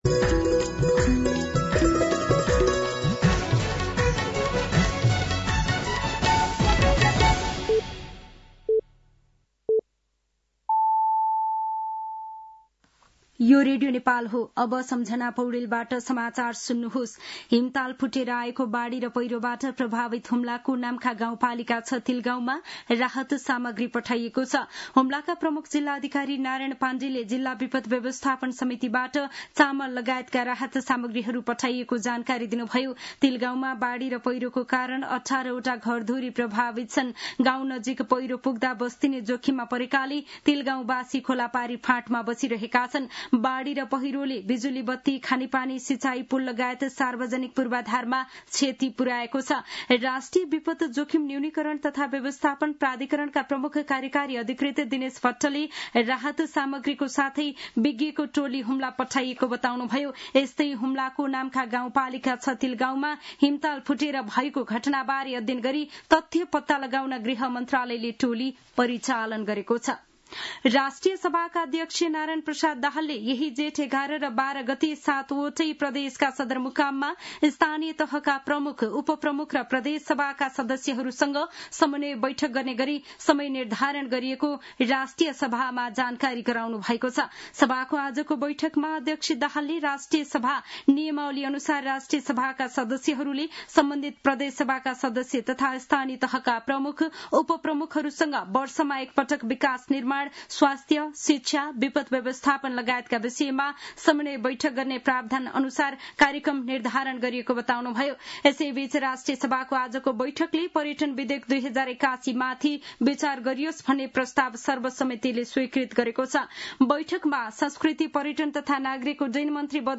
साँझ ५ बजेको नेपाली समाचार : ७ जेठ , २०८२